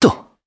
Esker-Vox_Landing_jp.wav